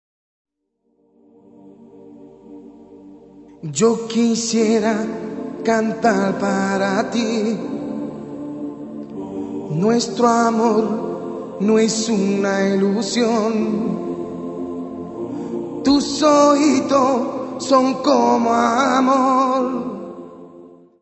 guitarra, voz e palmas
: stereo; 12 cm
Music Category/Genre:  World and Traditional Music